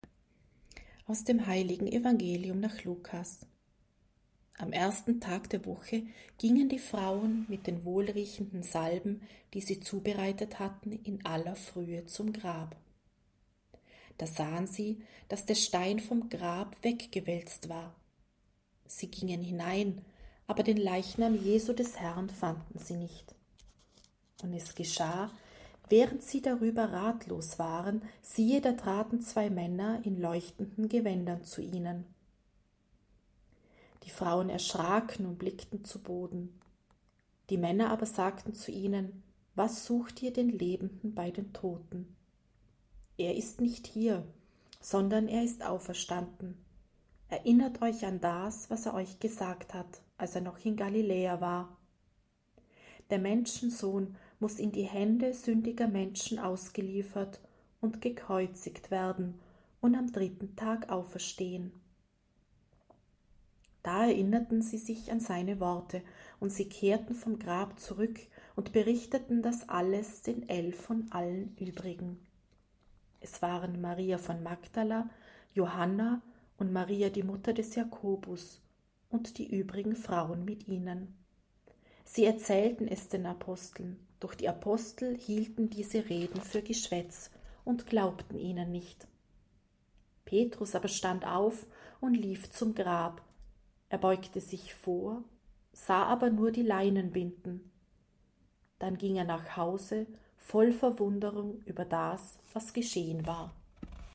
C-Osternacht-Evangelium.mp3